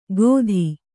♪ gōdhi